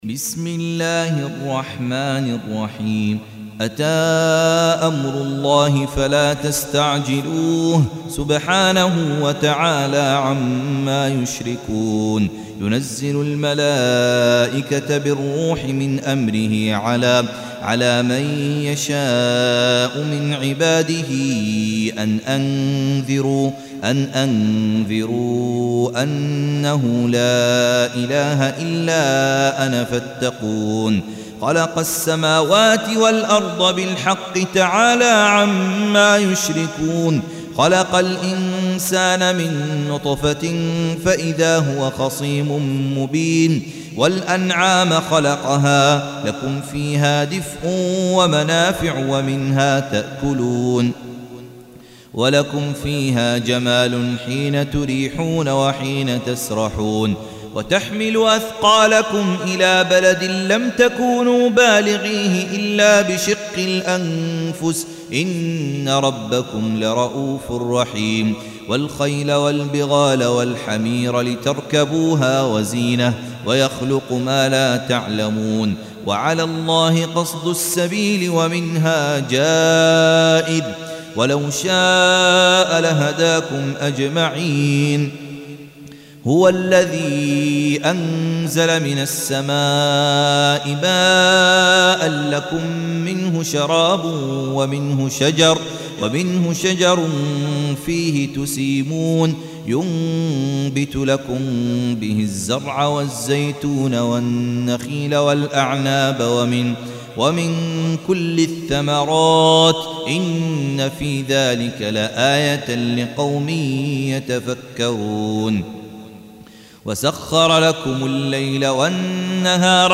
Surah Repeating تكرار السورة Download Surah حمّل السورة Reciting Murattalah Audio for 16. Surah An-Nahl سورة النحل N.B *Surah Includes Al-Basmalah Reciters Sequents تتابع التلاوات Reciters Repeats تكرار التلاوات